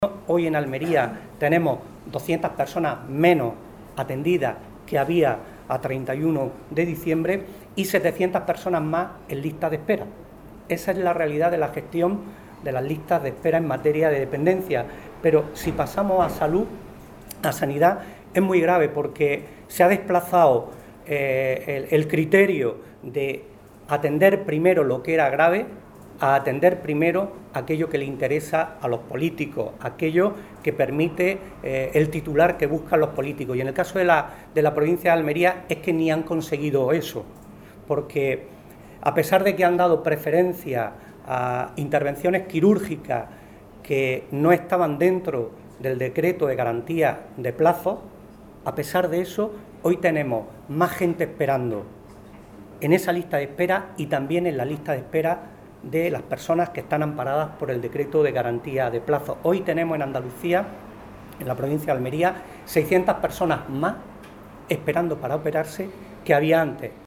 En una rueda de prensa en la que ha hecho un balance del curso político que ahora termina, el responsable del PSOE provincial ha acusado al “gobierno andaluz de derechas, que se sostiene gracias a la extrema derecha”, de haberse olvidado incluso de las promesas que realizó en campaña electoral.